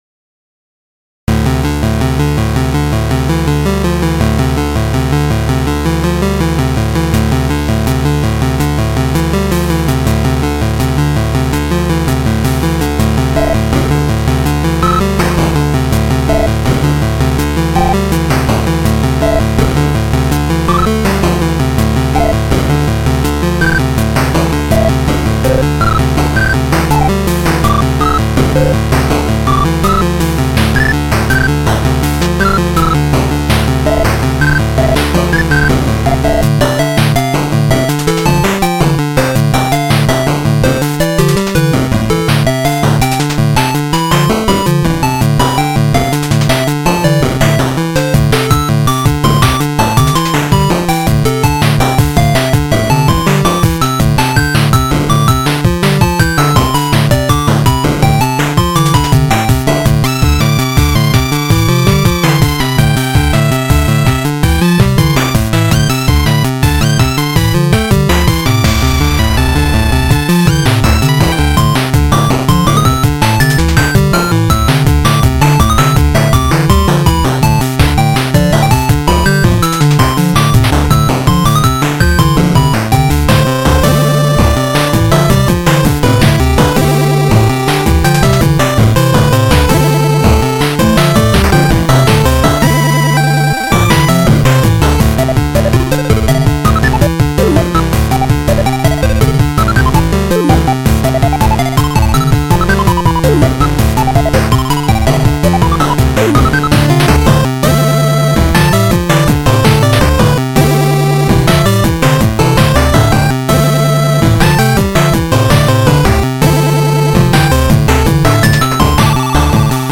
(2A03)